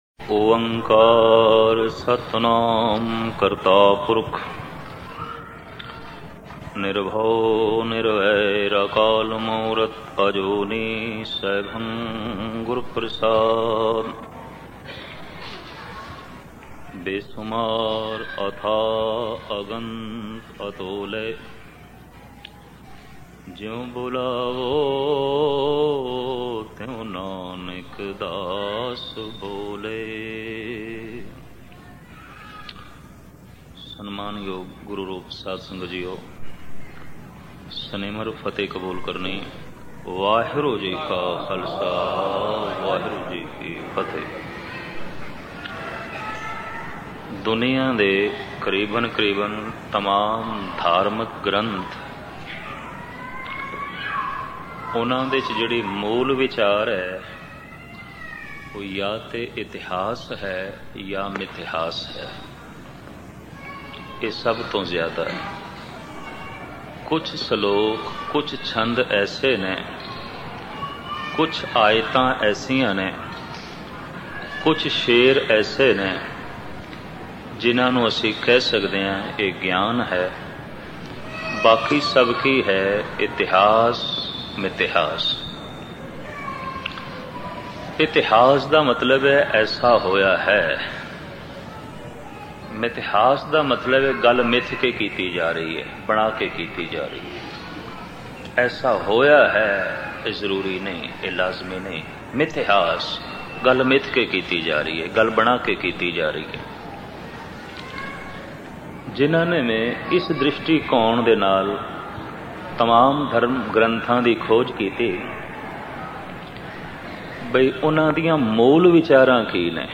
Katha
Album: Prakash Utsav Guru Granth Sahib Genre: Gurmat Vichar